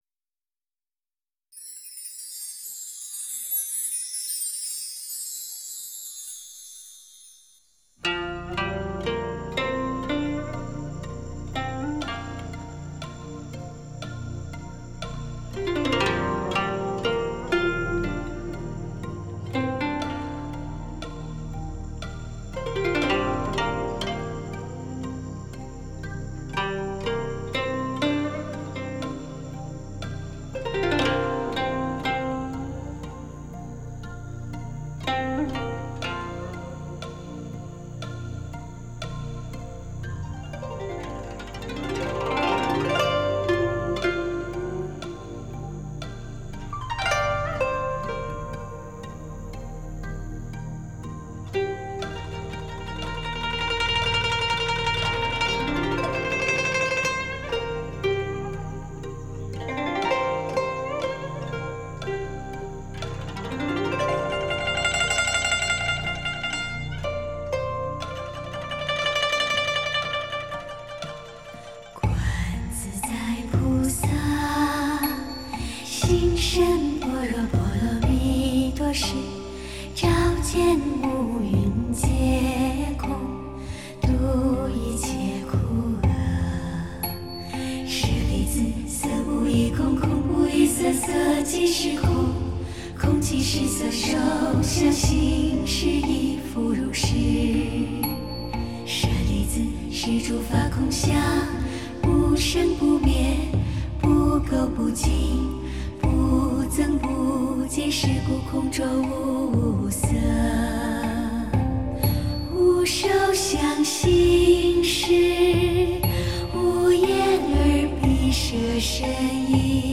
丝缎般的音质天衣无缝的纯净优雅和唱
创造出澎湃生动的音域环绕效果，
真正体验360度数码环绕音响效果。